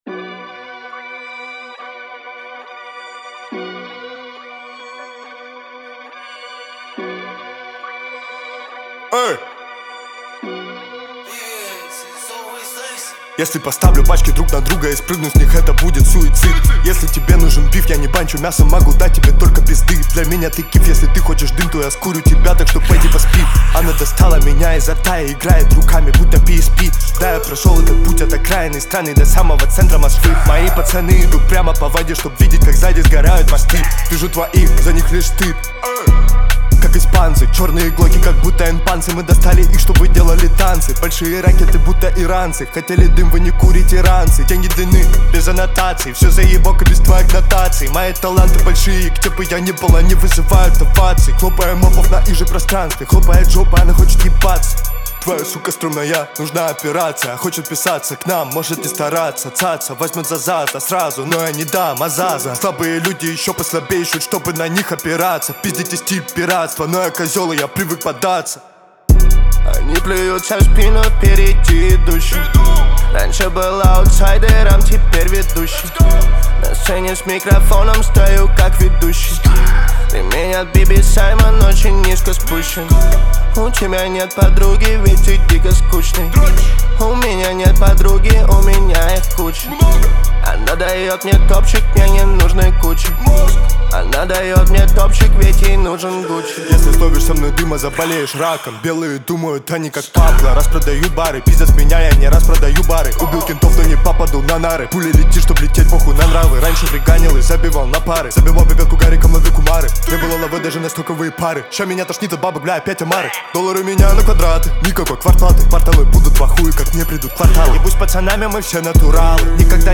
Категории: Русские песни, Рэп и хип-хоп.